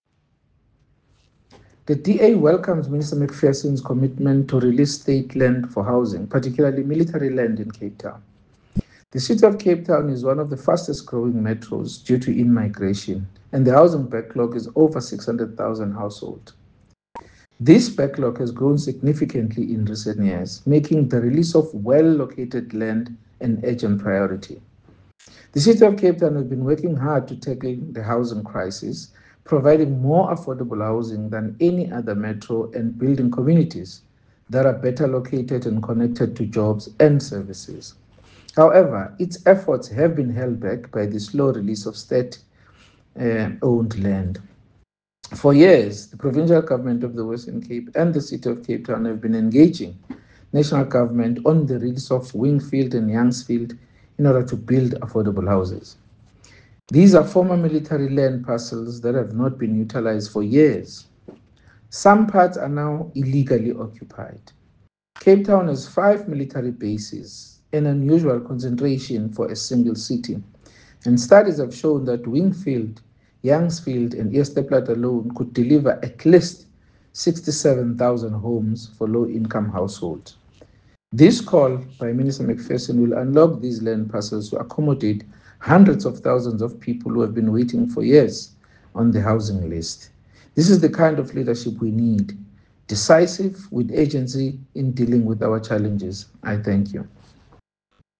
isiXhosa soundbite by Bonginkosi Madikizela MP.